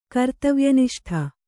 ♪ kartavya niṣṭha